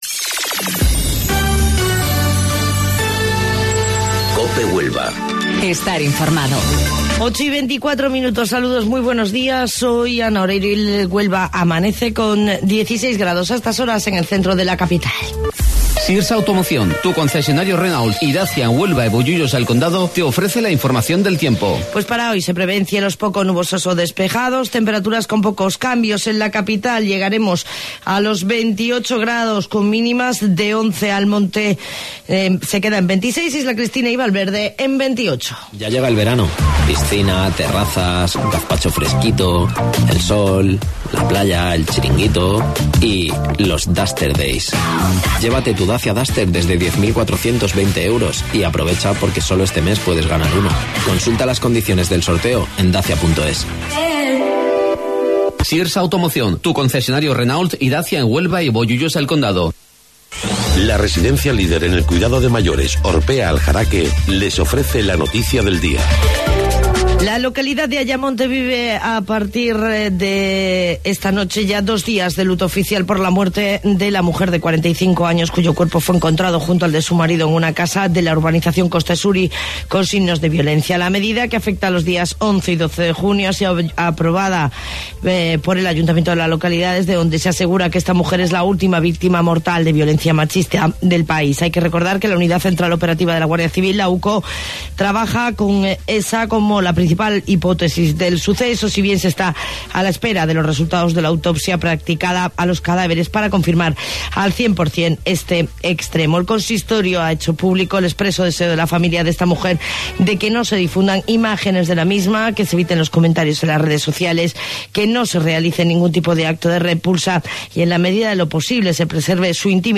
AUDIO: Informativo Local 08:25 del 11 de Junio